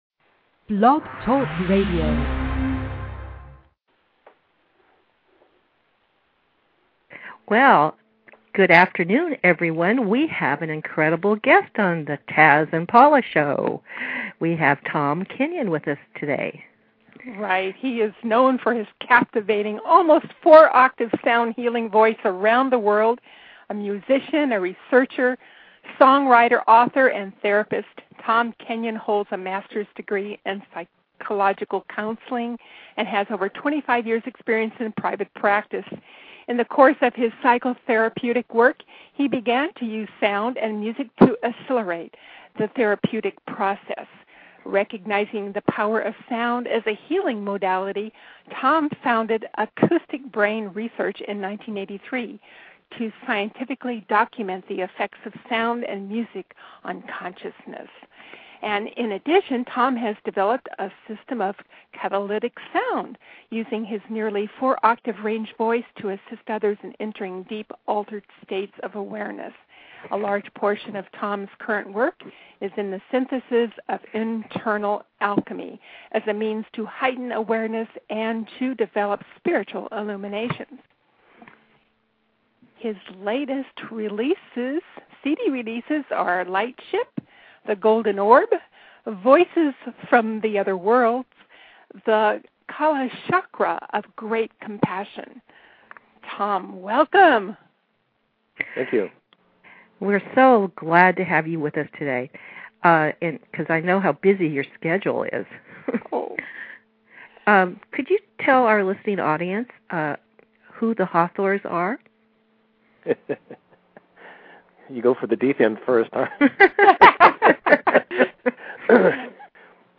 Their warm and intimate interviewing styles bring out the magnetic charm and essential thoughts of some of the most extraordinary people you will ever listen to.